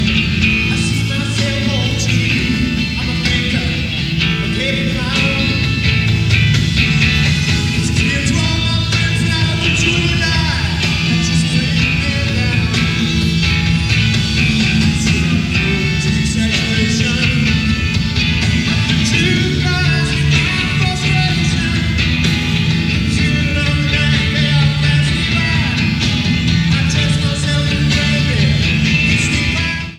Format/Rating/Source: CD - C- - Audience
Comments: Fair audience recording
Sound Samples (Compression Added):